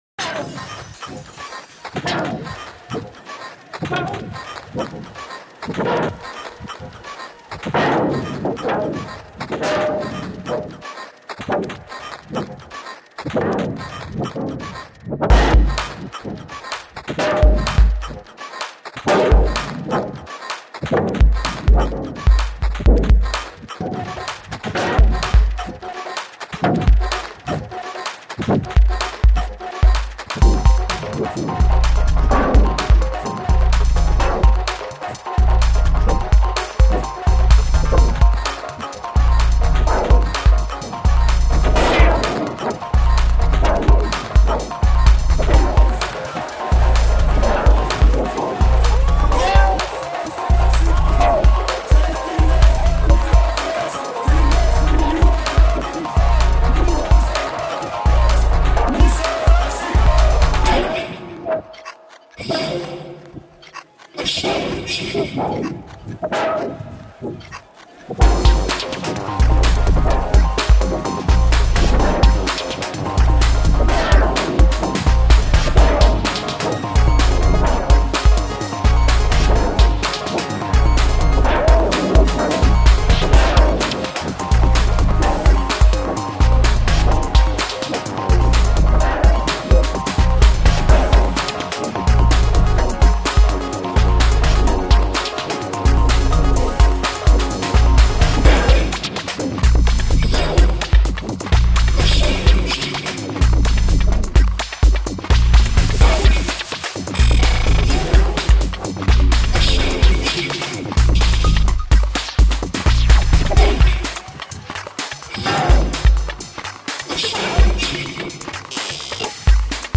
Greek anti-austerity protest reimagined